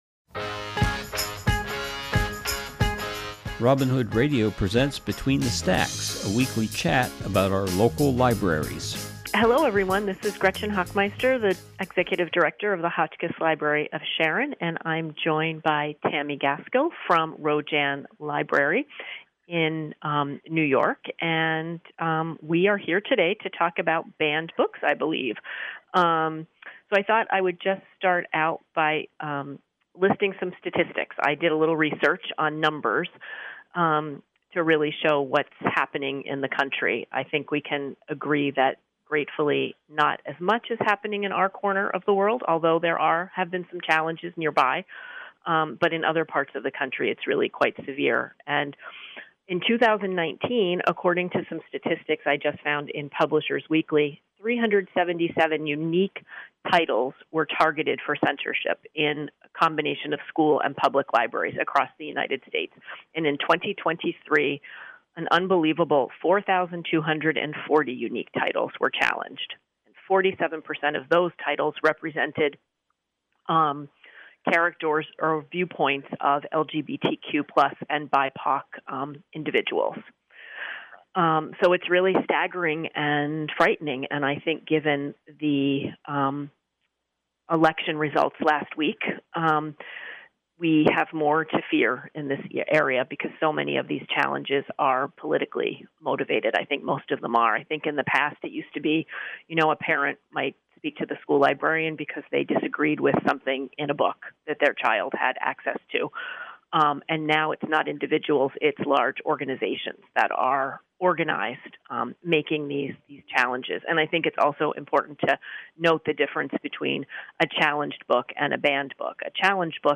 This program is a conversation